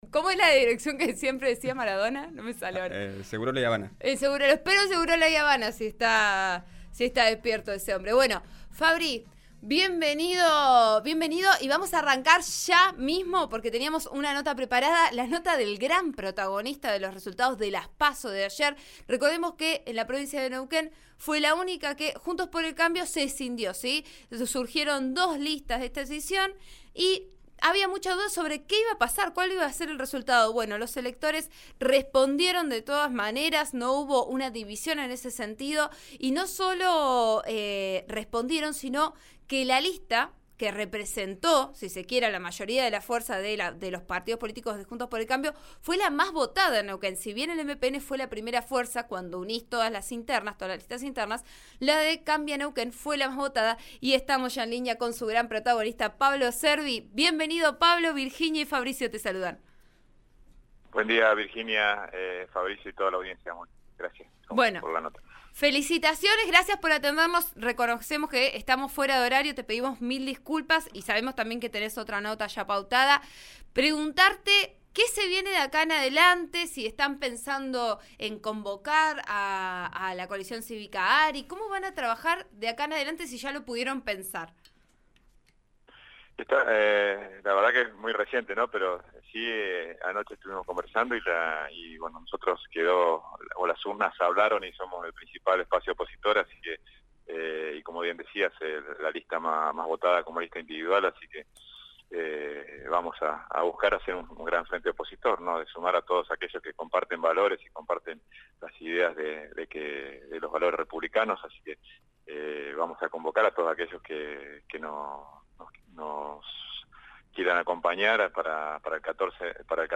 «Las urnas hablaron y somos el principal espacio opositor. La lista más votada a nivel individual, así que vamos a buscar hacer un gran frente opositor. Sumar todos aquellos que comparten valores y las ideas de los valores republicanos. Vamos a convocar a todos aquellos que nos quieran acompañar para el 14 de noviembre», sostuvo Pablo Cervi, en declaraciones al programa Vos a Diario, de RN RADIO (89.3).
Durante la entrevista también hizo una reflexión sobre los resultados generales en Neuquén.